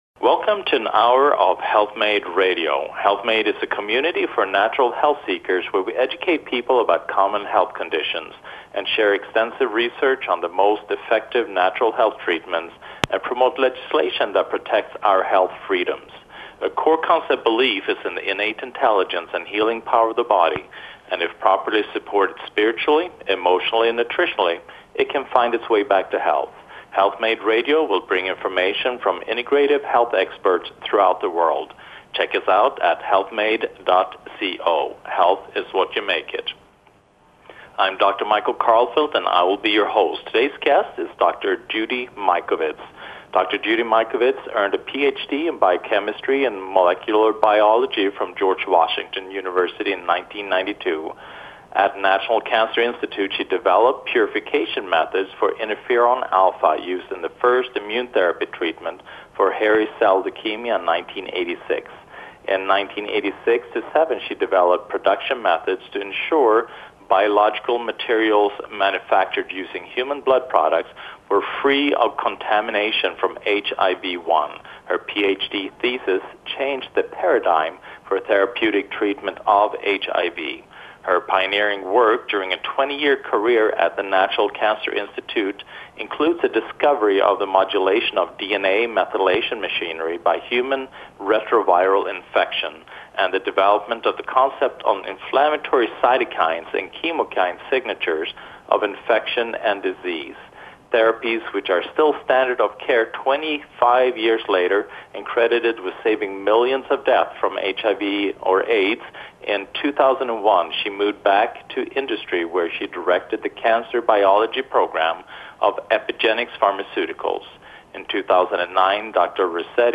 What's Next? Interview with Dr. Judy Mikovitis of Plandemic and Plague of Corruption